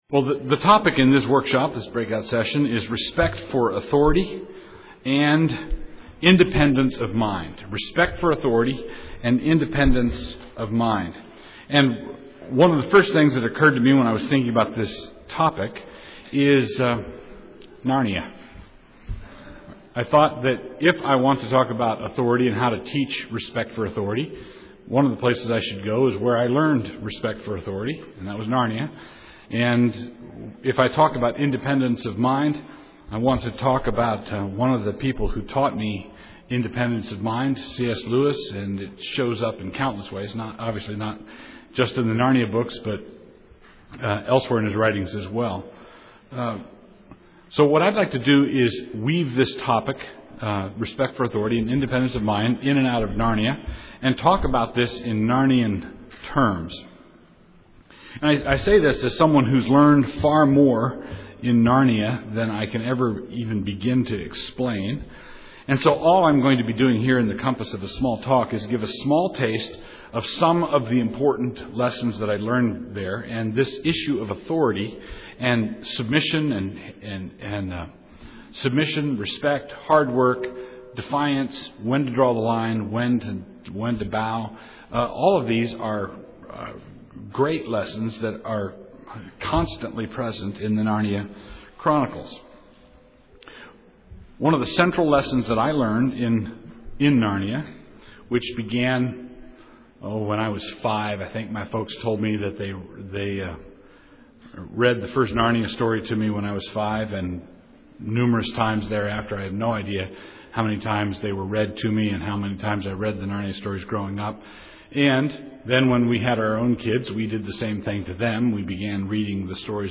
2007 Workshop Talk | 0:56:12 | All Grade Levels, Virtue, Character, Discipline
He is the author of numerous books on classical Christian education, the family, and the Reformed faith Additional Materials The Association of Classical & Christian Schools presents Repairing the Ruins, the ACCS annual conference, copyright ACCS.